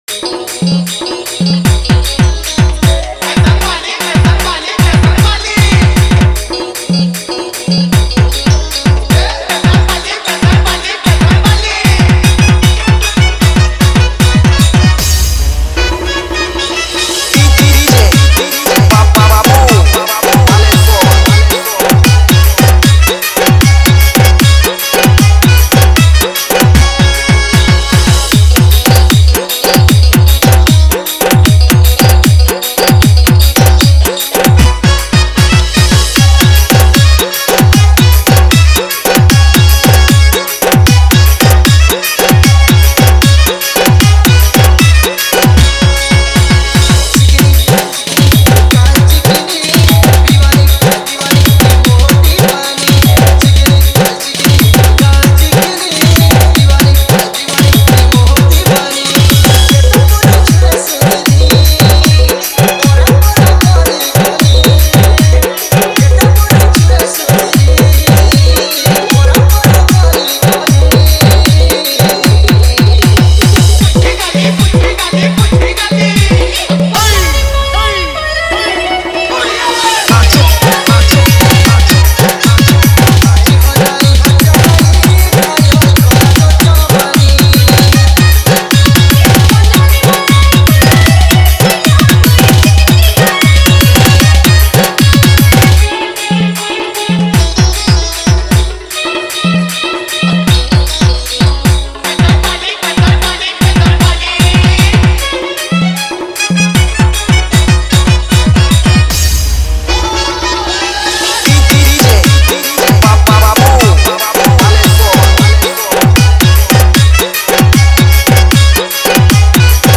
Category:  Sambalpuri Dj Song 2022